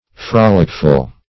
Meaning of frolicful. frolicful synonyms, pronunciation, spelling and more from Free Dictionary.
frolicful - definition of frolicful - synonyms, pronunciation, spelling from Free Dictionary Search Result for " frolicful" : The Collaborative International Dictionary of English v.0.48: Frolicful \Frol"ic*ful\, a. Frolicsome.